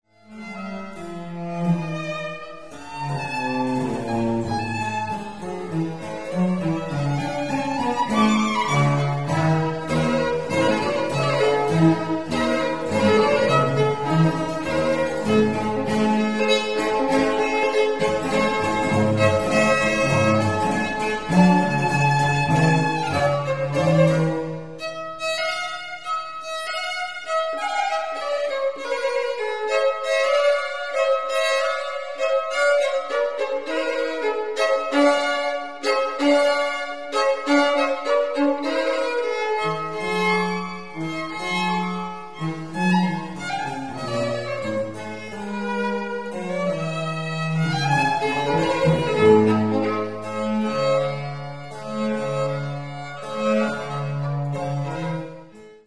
1989 first world recording on period instruments